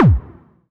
Index of /90_sSampleCDs/Club_Techno/Percussion/Kick
Kick_26.wav